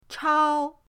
chao1.mp3